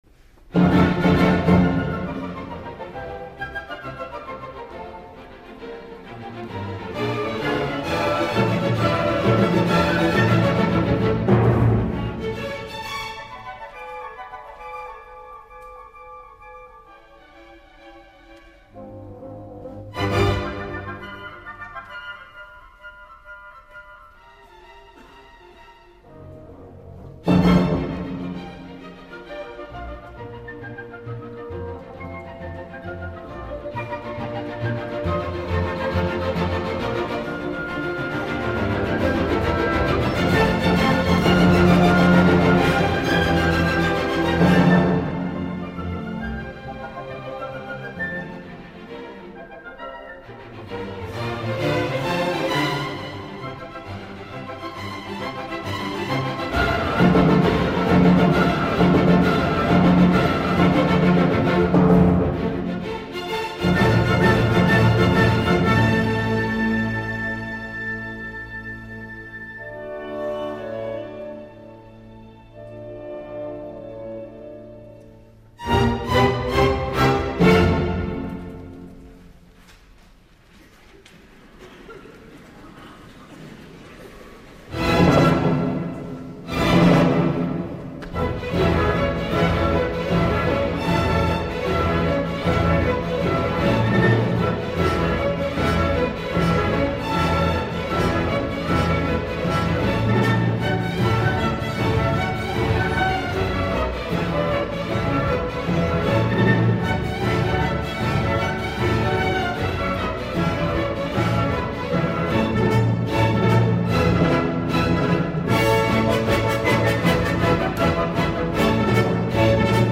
• West–Eastern Divan Orchestra
• Daniel Barenboim conductor
Què us semblaria escoltar l’Allegro con brio que tanca l’exultant setena simfonia?
allegro-con-brio.mp3